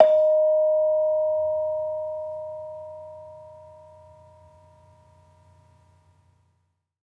Bonang-D#4-f.wav